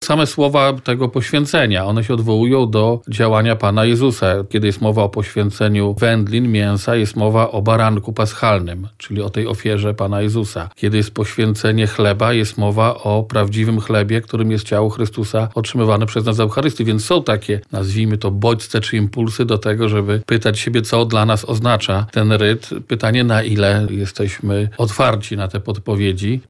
Odbywające się w Wielką Sobotę poświęcenie pokarmów to tradycja, która może otwierać wiernych na tajemnicę Jezusa Chrystusa – mówi biskup Adam Bab, biskup pomocniczy Archidiecezji Lubelskiej.